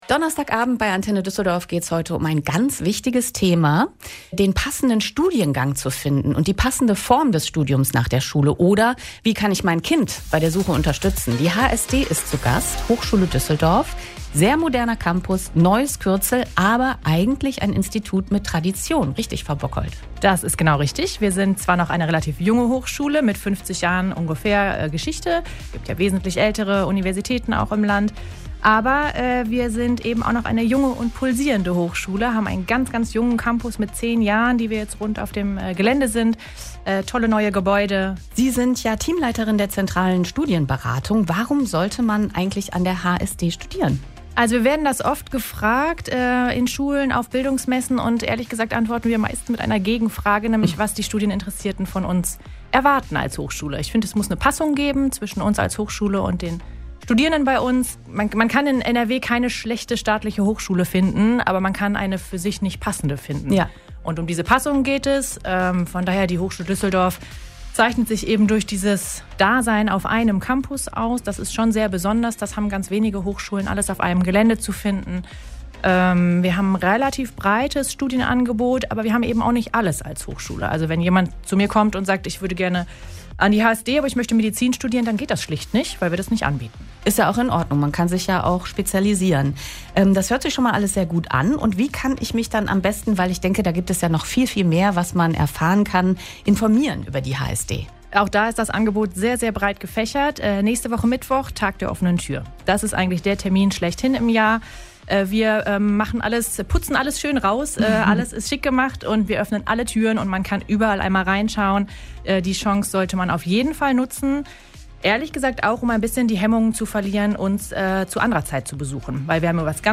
Das HSD-Team war am 12.06.2025 bei Antenne Düsseldorf zu Gast und hat einiges über den Tag der offenen Tür erzählt.